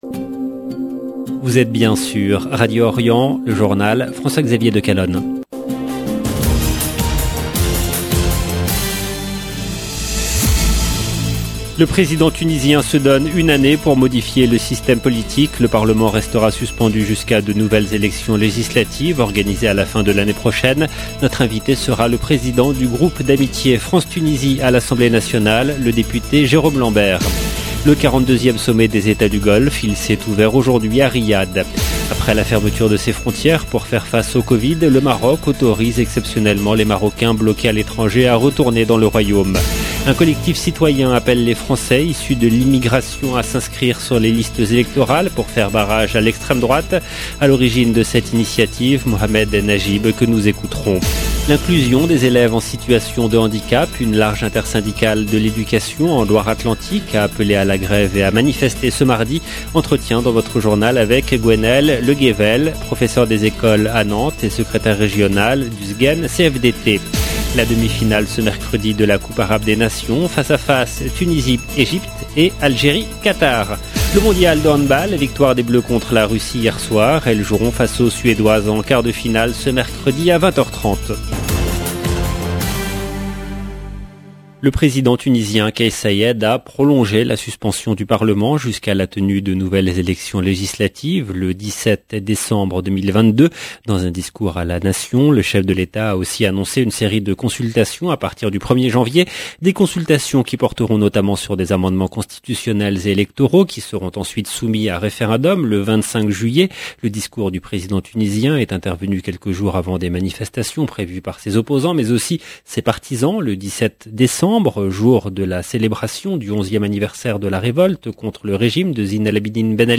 LE JOURNAL DU SOIR EN LANGUE FRANCAISE DU 14/12/21 LB JOURNAL EN LANGUE FRANÇAISE
Notre invité sera le président du groupe d'amitié France Tunisie à l’Assemblée nationale, le député Jérôme Lambert. Le 42e sommet des Etats du Golfe s’est ouvert aujourd’hui à Riyad.